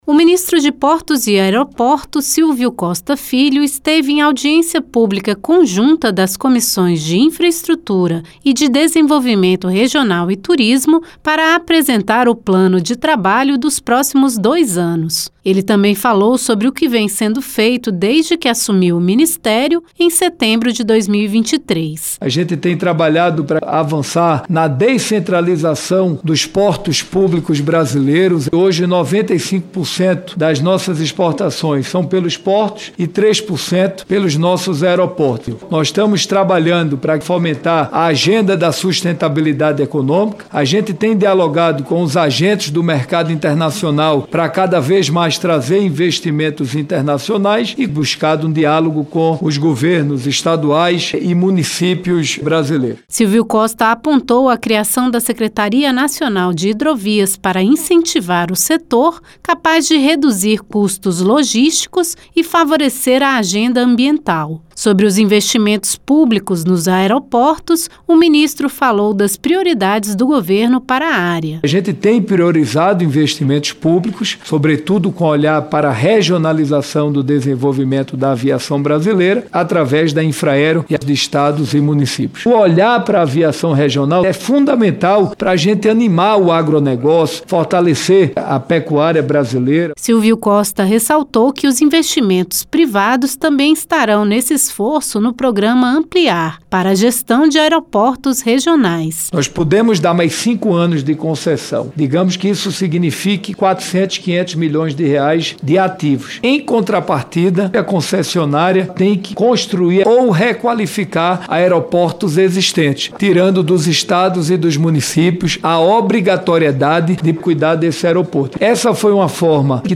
As Comissões de Infraestrutura (CI) e de Desenvolvimento Regional e Turismo (CDR) receberam o ministro de Portos e Aeroportos, Silvio Costa Filho, para falar do trabalho planejado para os próximos dois anos da pasta. O senador Marcos Rogério (PL-RO), presidente da CI, conduziu a audiência pública feita a pedido das senadoras Professora Dorinha Seabra (União-TO) e Augusta Brito (PT-CE) e do senador Confúcio Moura (MDB-RO).